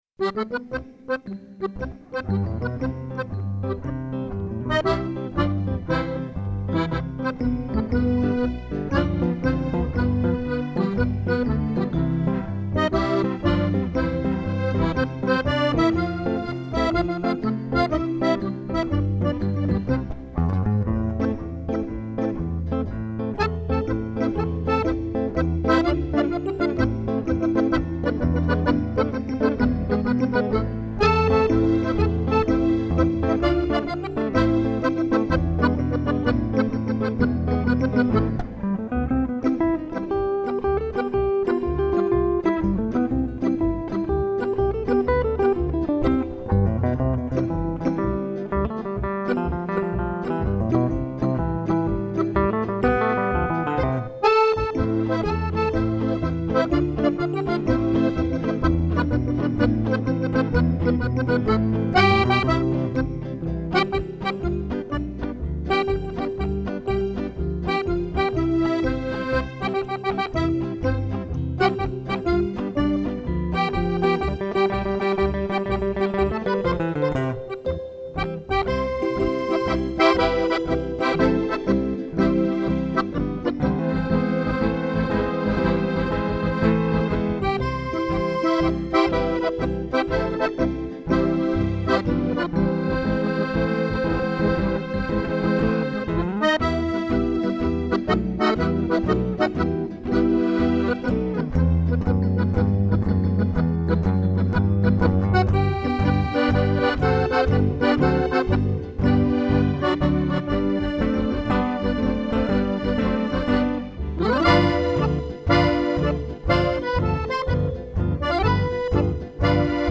Accordian Duo
This duo includes two skilled musicians that blend the accordian and guitar with perfection. Their sound is smooth and always pleasing to any audience.